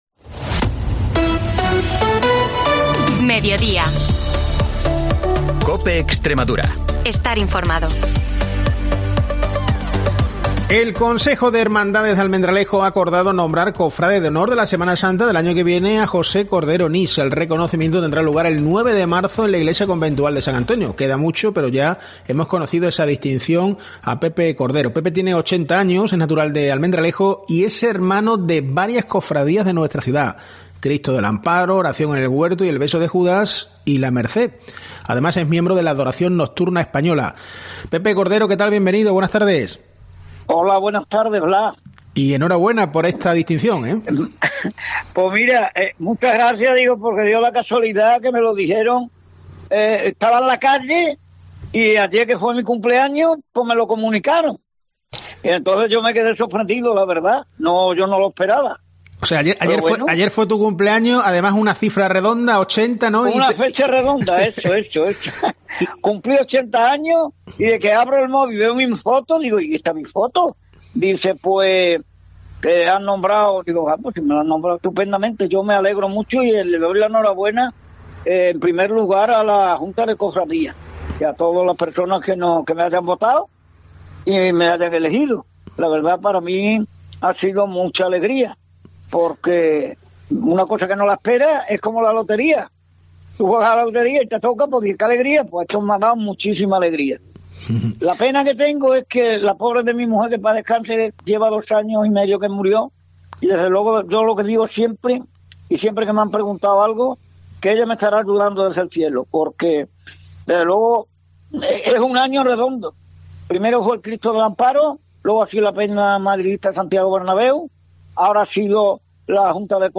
AUDIO: Información y entrevistas de Almendralejo-Tierra de Barros y Zafra-Río Bodión, de lunes a jueves, de 13.50 a 14 horas